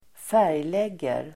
Folkets service: färglägga färglägga verb, colour , tint Grammatikkommentar: A & x Uttal: [²f'är:jleg:er] Böjningar: färglade, färglagt, färglägg, färglägga, färg|lägger Synonymer: måla Definition: sätta färg på